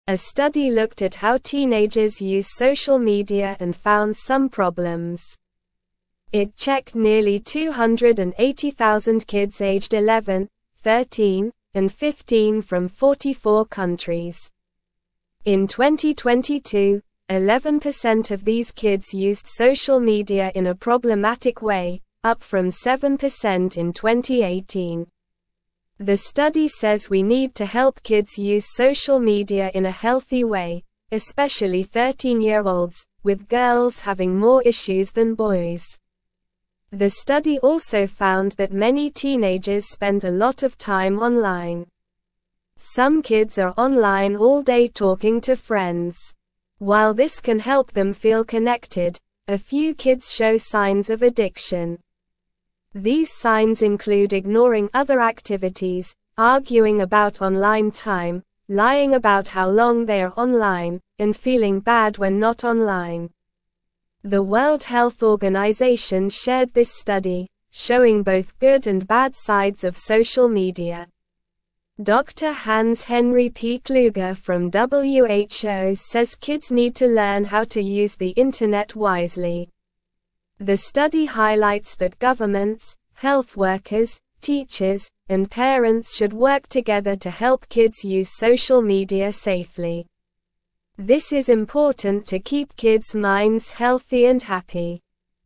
Slow
English-Newsroom-Lower-Intermediate-SLOW-Reading-Kids-Using-Social-Media-More-Since-Pandemic-Started.mp3